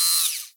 Sfx_tool_spypenguin_cam_movement_end_01.ogg